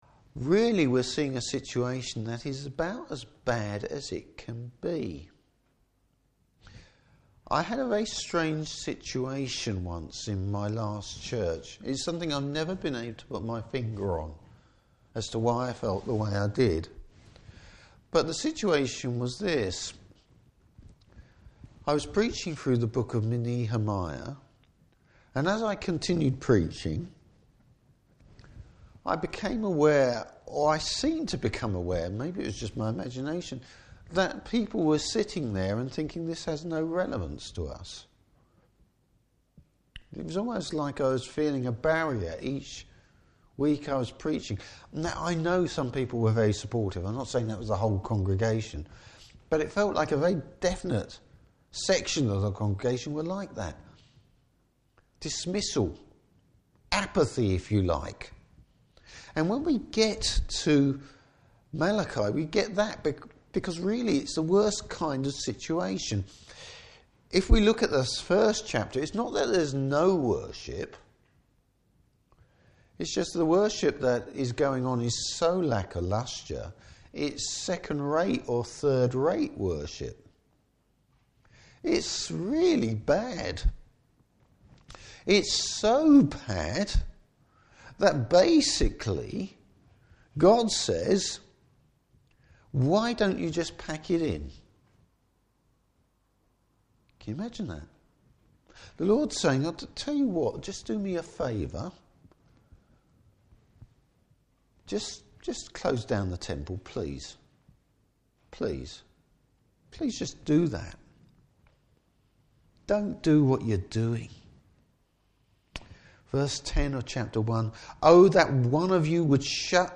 Service Type: Morning Service The Lord comes suddenly and unexpectedly!